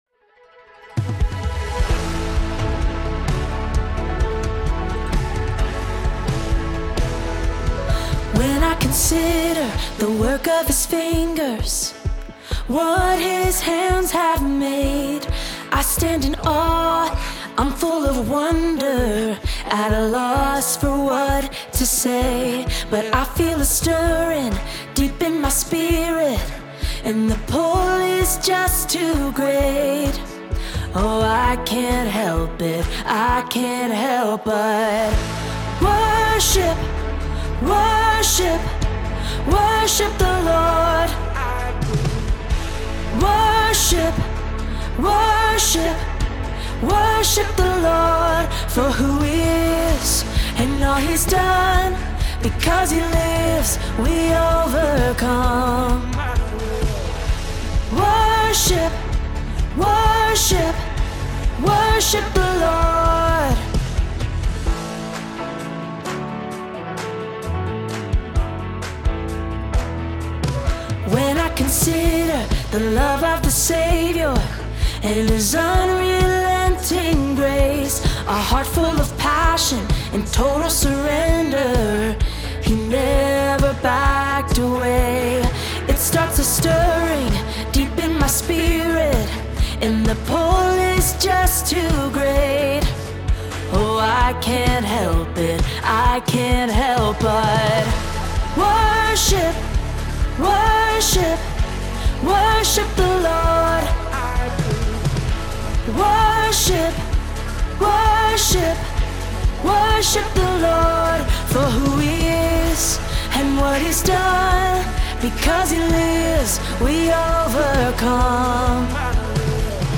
Female Vocals